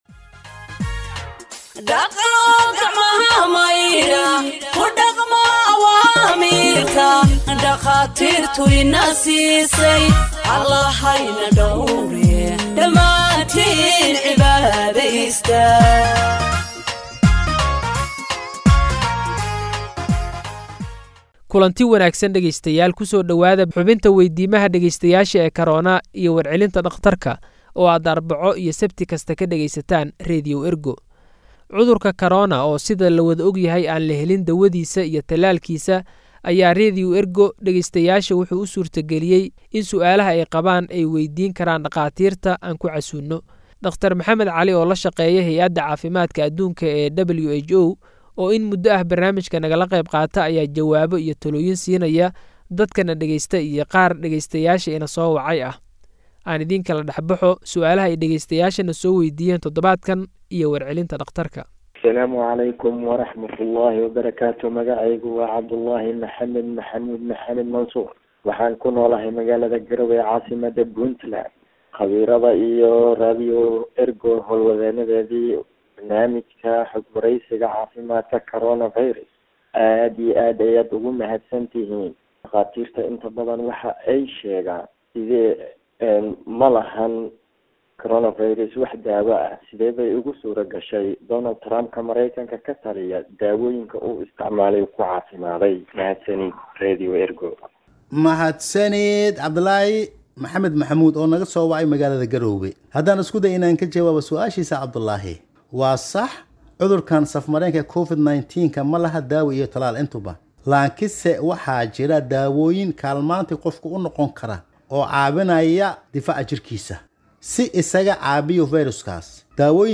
Health expert answers listeners’ questions on COVID 19 (28)